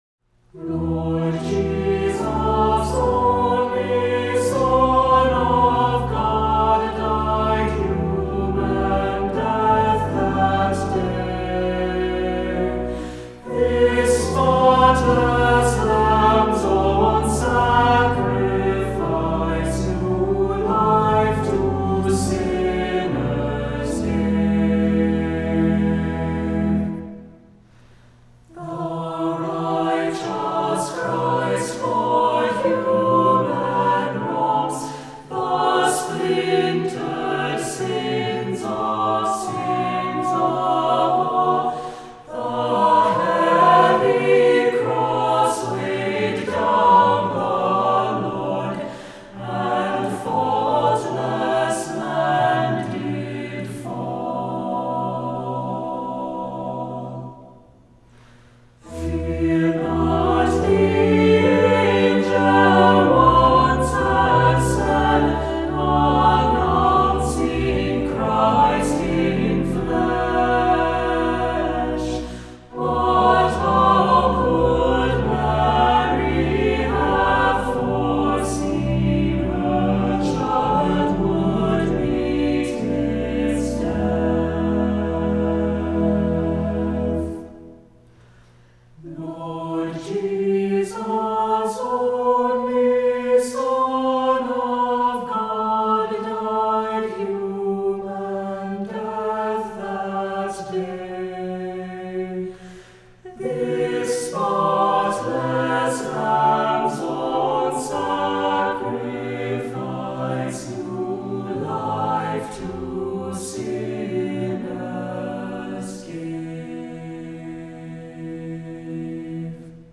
Voicing: SATB; optional Descant; Cantor; Assembly